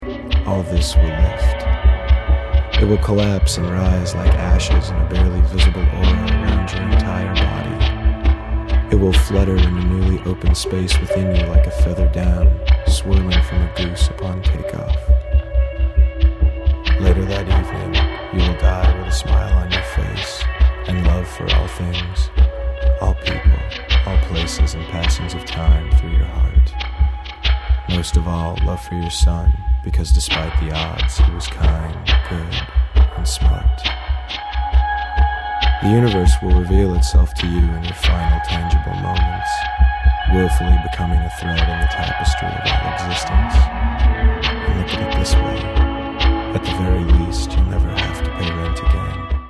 " which is a short story spoken over a drum loop.
added the drum machine